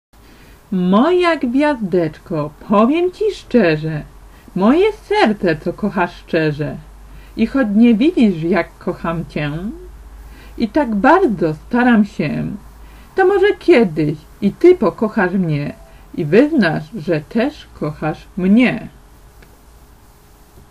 Audiobook z wierszykiem "Gwiazdeczka"Słuchaj